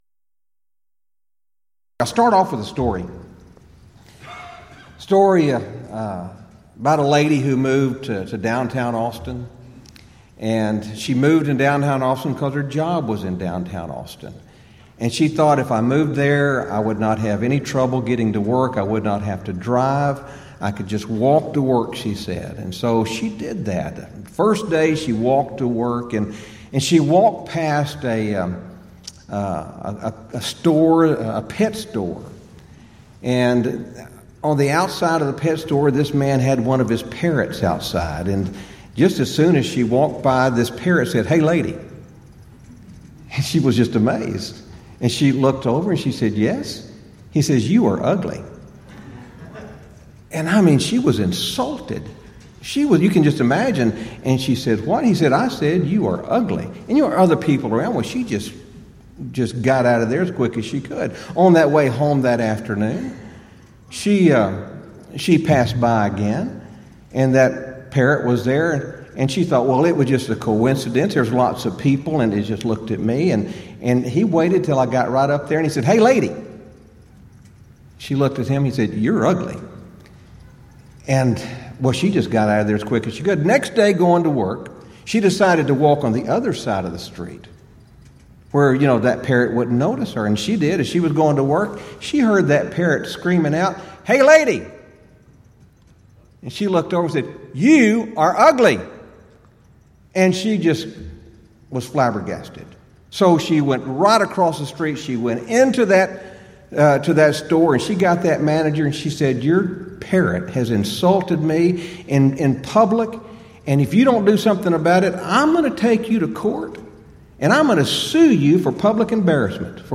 Event: 33rd Annual Southwest Lectures Theme/Title: Equipping To Serve: Lessons from the Pastoral Epistles
lecture